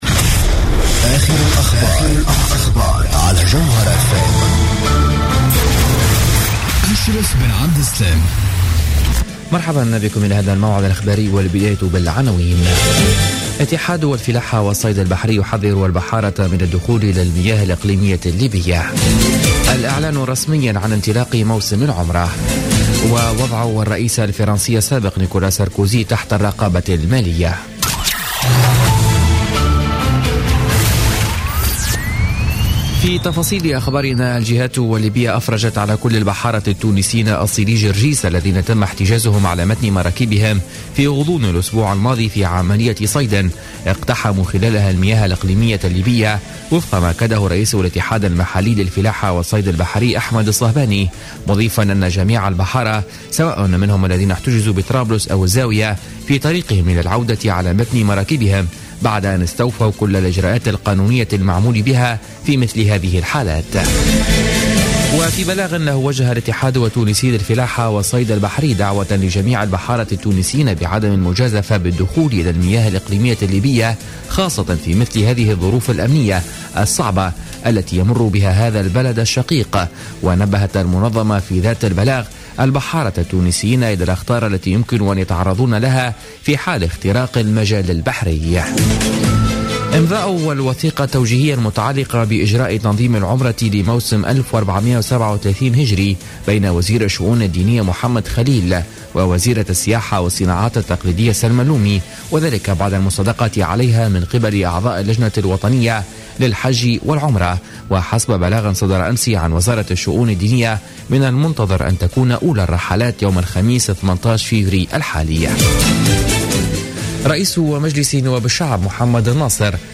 نشرة أخبار منتصف الليل ليوم الاربعاء 17 فيفري 2016